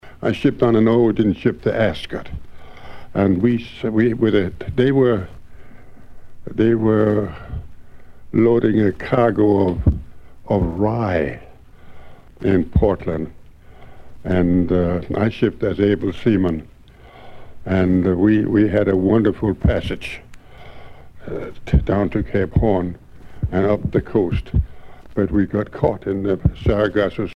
Témoignages et chansons maritimes
Catégorie Témoignage